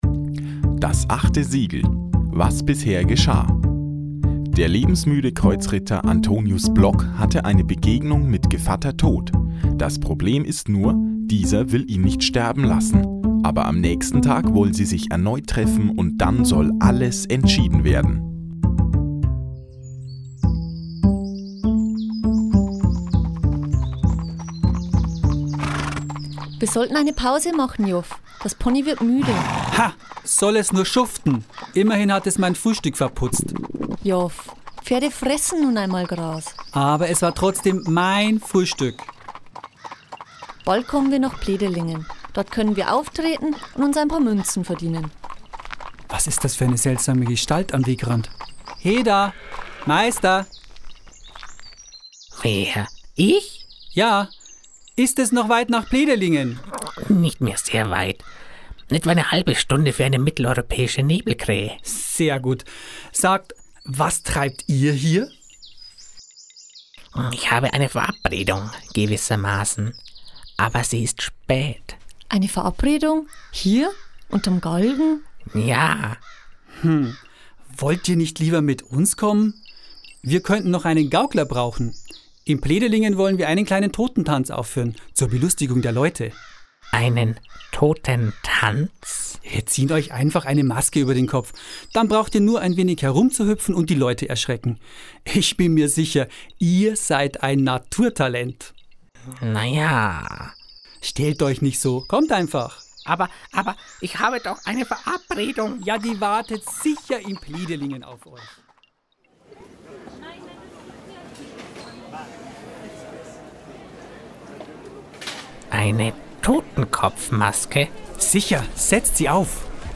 16. Hörspiel
hoerspiel_dasAchteSiegel_teil2.mp3